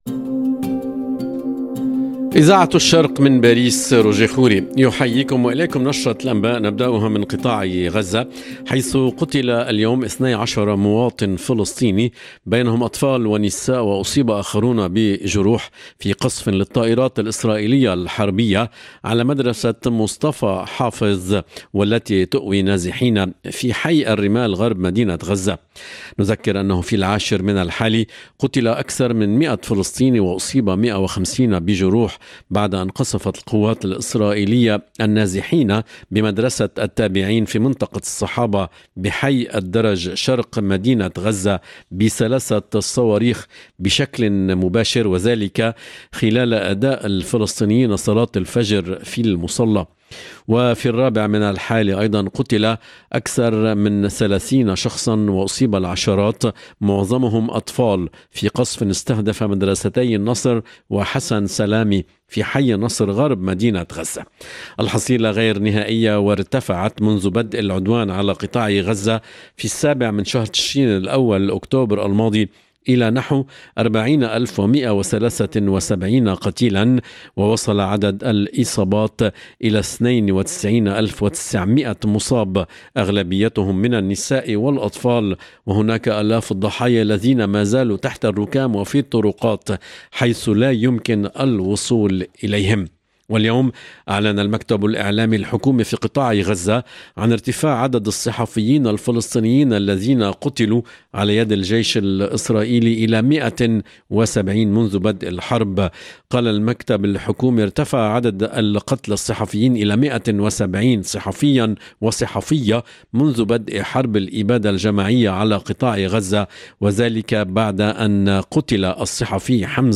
EDITION DU JOURNAL DU SOIR EN LANGUE ARABE DU 20/8/2024